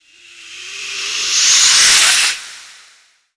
dark_wind_suck_conjure_01.wav